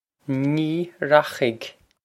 Pronunciation for how to say
Nee rokh-ig
This is an approximate phonetic pronunciation of the phrase.